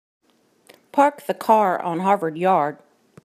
Because my accent (NC Appalachian, if there is such a thing) has been both a help and a hindrance during my life.
Here is me repeating the famous phrase:
Definitely not from Boston.